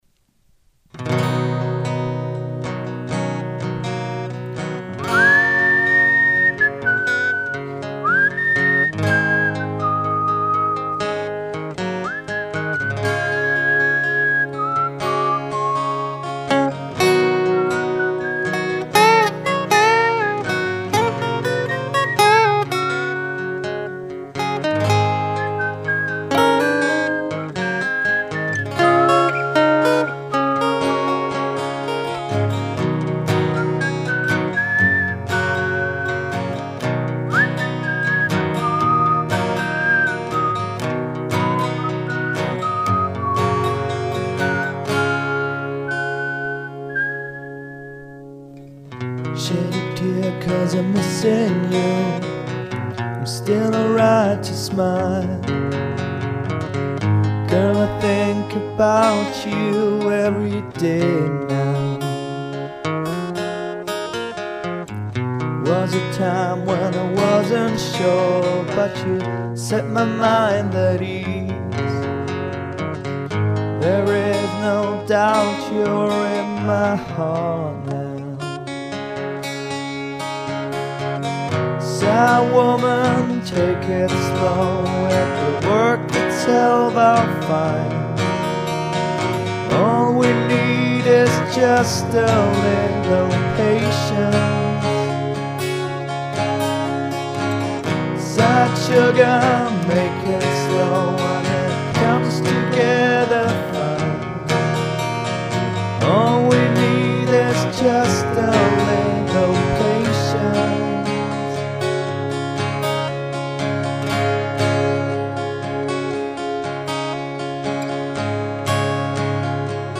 I got 3 guitar and 3 vocal tracks on it.
at 1:12 it sounds doubled